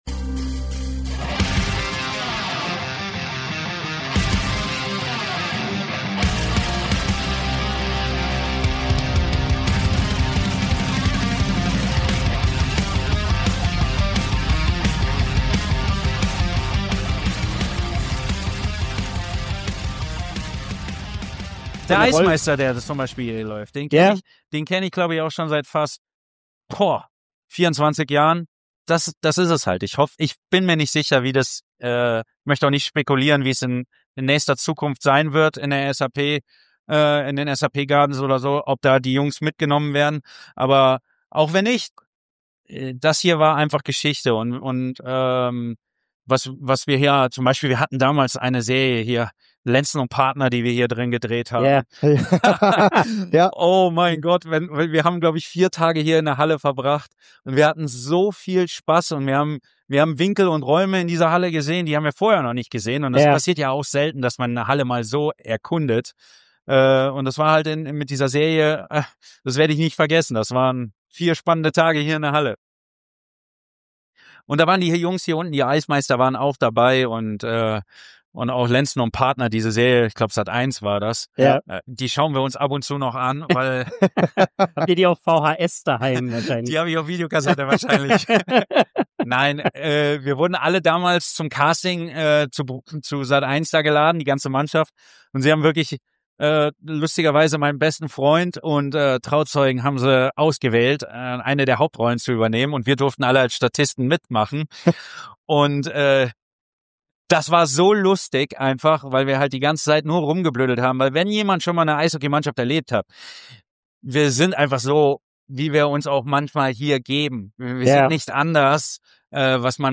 - Original-Radioübertragung aus dem Jahr 2024 - In fünf Teilen veröffentlicht: Jede eine einzigartige Episode